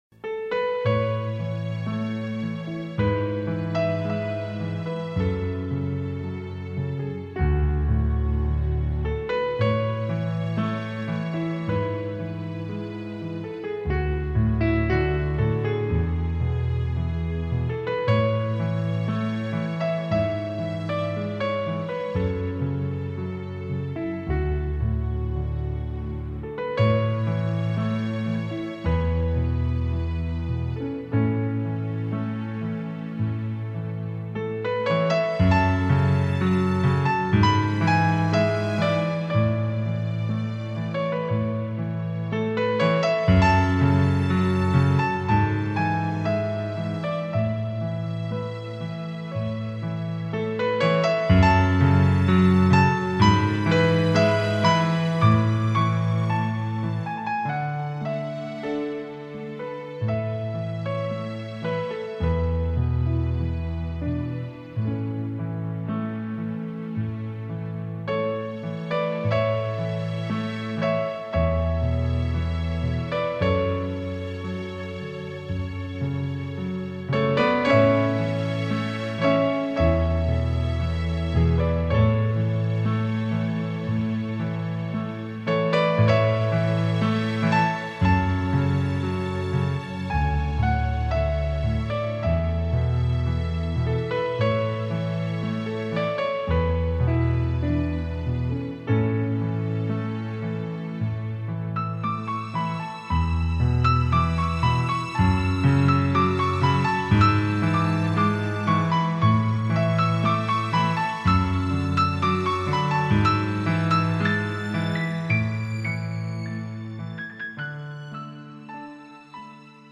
een album van rustgevende piano muziek.